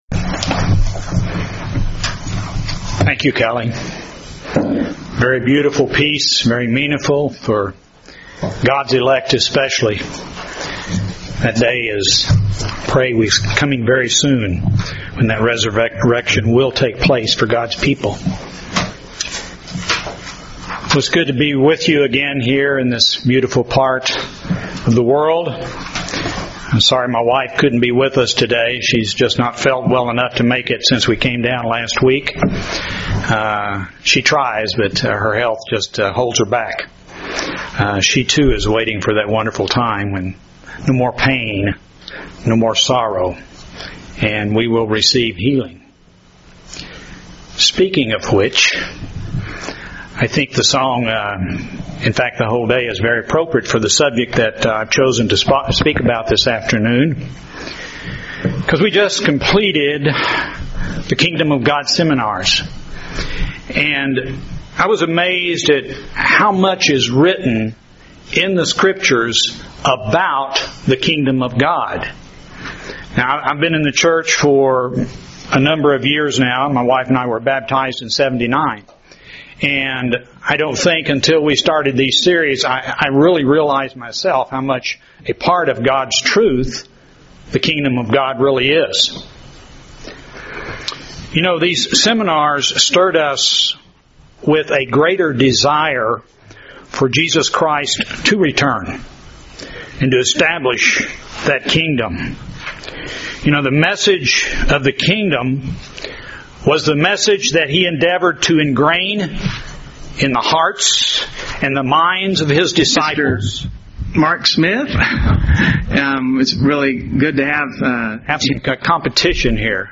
Feast of Trumpets - PM, Second Message During Jesus Christ's earthly ministry, He healed the sick on many occasions and gave power and authority for his disciples to do the same in His name.